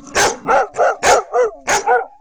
dog.wav